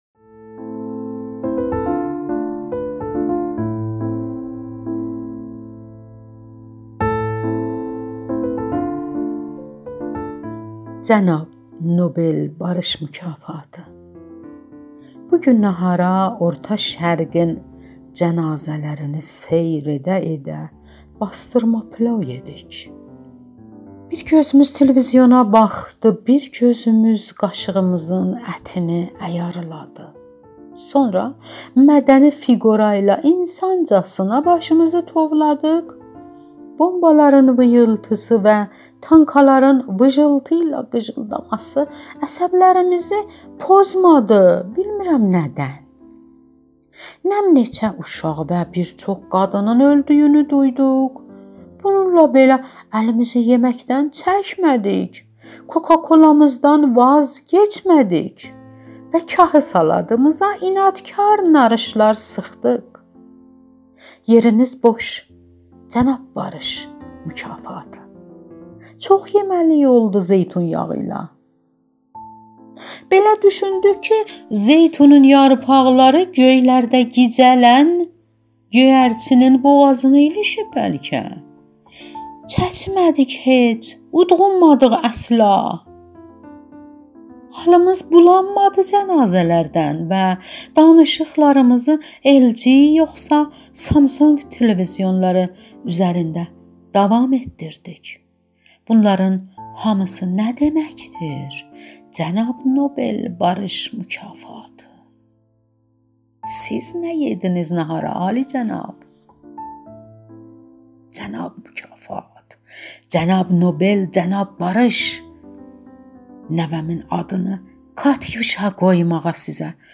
• سس, شعر